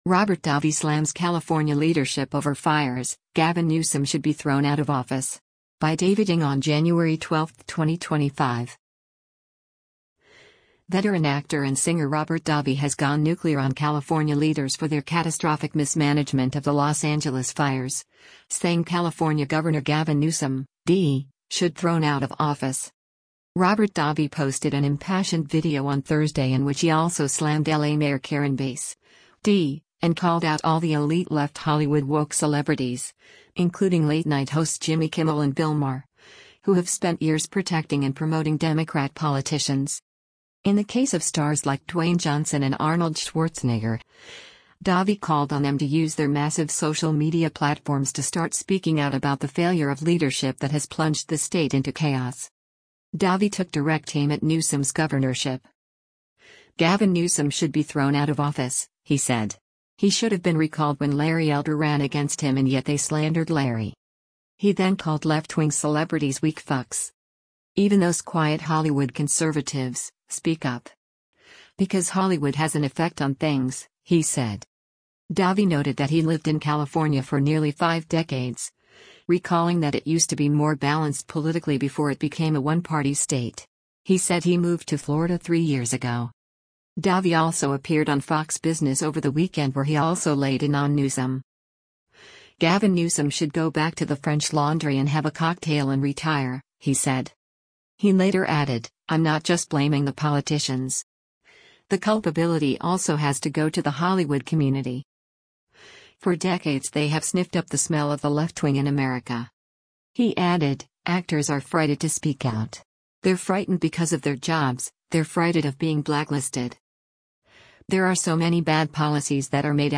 Robert Davi posted an impassioned video on Thursday in which he also slammed L.A. Mayor Karen Bass (D) and called out all the “elite left Hollywood woke” celebrities — including late-night hosts Jimmy Kimmel and Bill Maher — who have spent years protecting and promoting Democrat politicians.